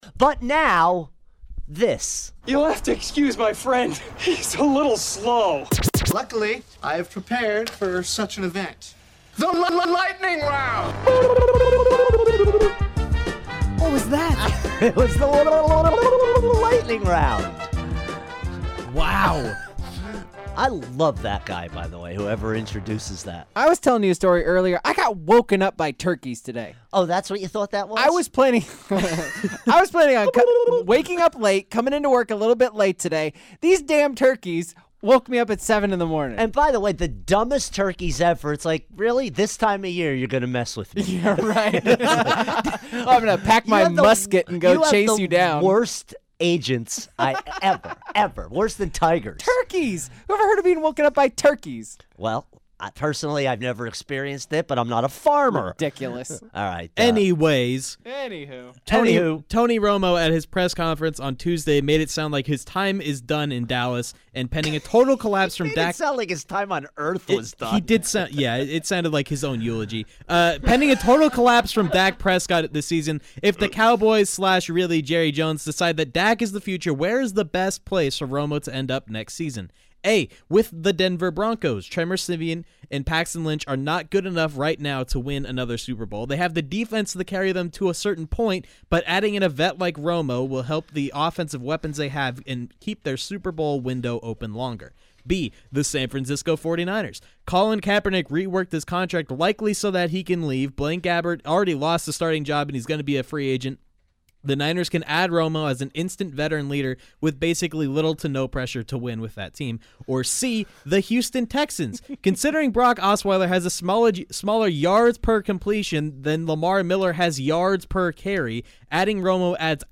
fires through the days biggest sports stories rapid fire style